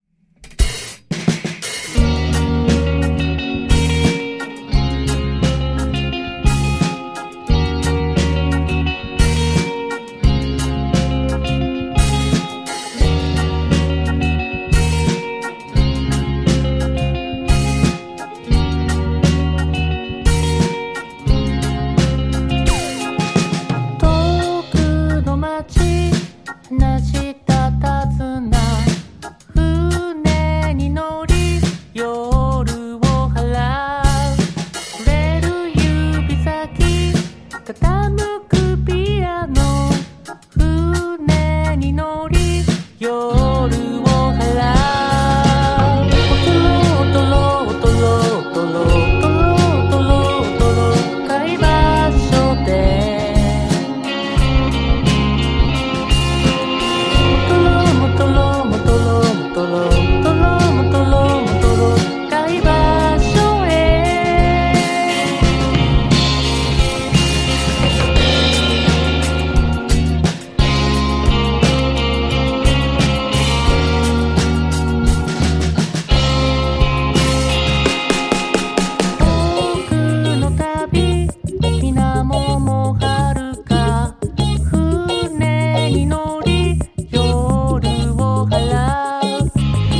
下北沢を中心に活動するインディーバンド
CITY POP / AOR# 90-20’S ROCK